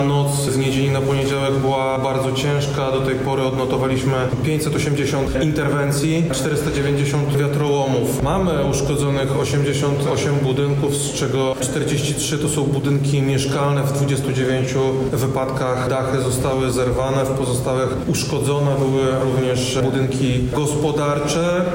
Dziś (07.04) odbył się briefing prasowy w Lubelskim Urzędzie Województwa w Lublinie poświęcony sytuacji pogodowej z ostatnich 48 godzin.
O szczegółach mówi Wojewoda Lubelski Krzysztof Komorski: